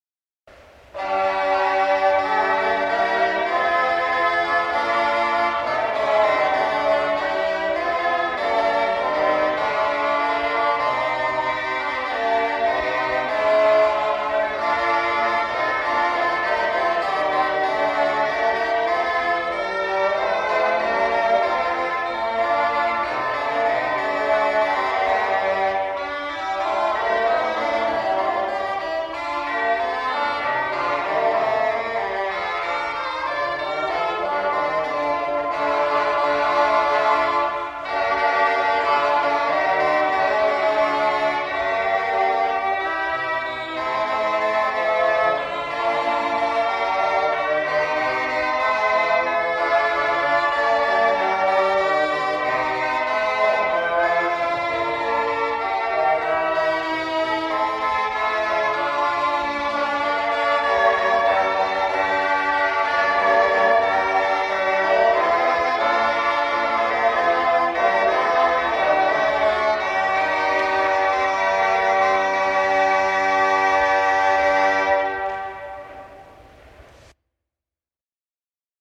. the other “Noe, noe, noe” but lacking further text, which makes it fair game as an instrumental piece for Christmas . . . with shawms and sackbutt, and full of cascading imitation.